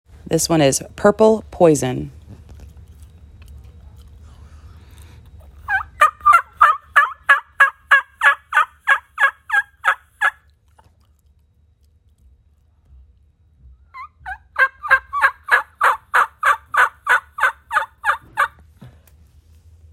Diaphragm Calls.